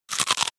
Download Free Cartoon Eating Sound Effects | Gfx Sounds
Indulge in the satisfying cartoon crunches, munches, and bites.
Cartoon-crunching-bite-7.mp3